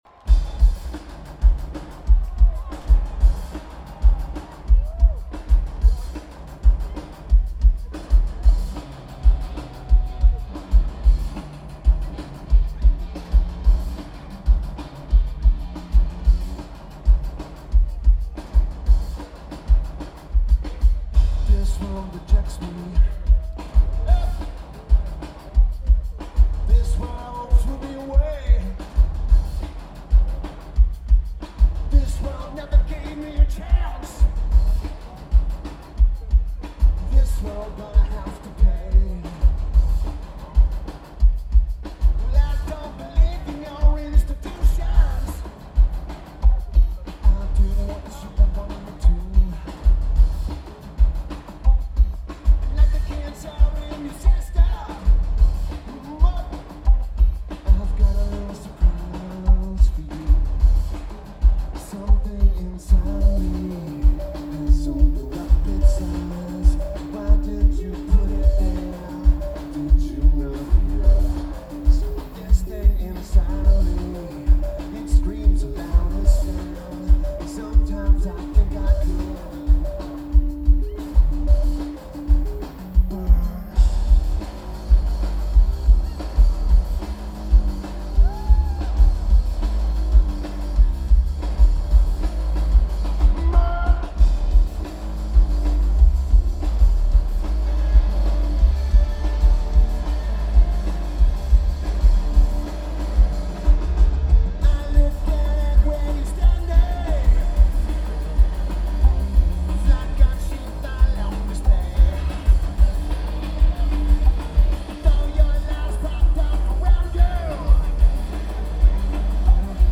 Shaky Knees Music Festival
Keyboards/Bass/Backing Vocals
Drums
Guitar
Lineage: Audio - AUD (DPA 4028 + Sony PCM-A10)